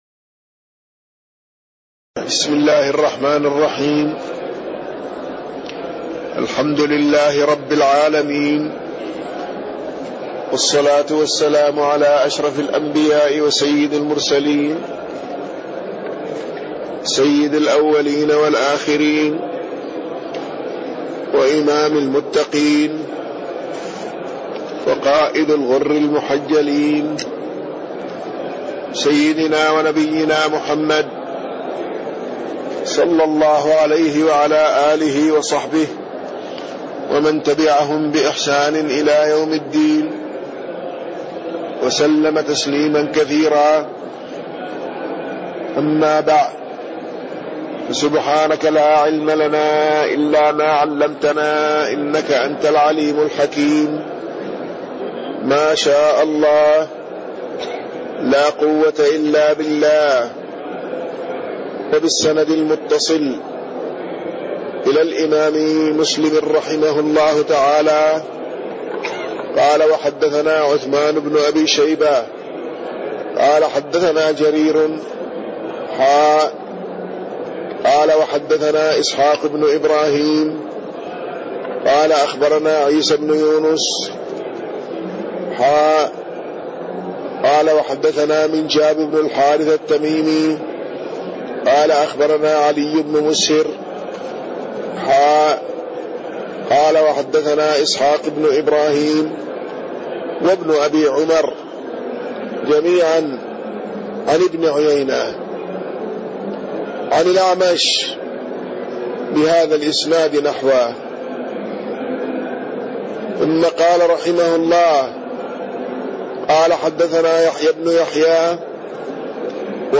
تاريخ النشر ١٣ ربيع الأول ١٤٣٢ هـ المكان: المسجد النبوي الشيخ